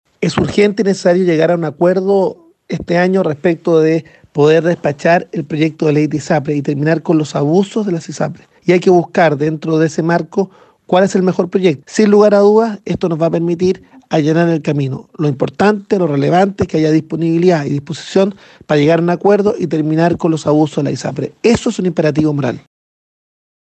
El senador RN Francisco Chahuán, quien integra la Comisión de Salud, declaró que aún tienen que analizar la fórmula del exministro Emilio Santelices junto a la del actual Secretario de Estado, Jaime Mañalich, y que esperan llegar a un acuerdo político este año.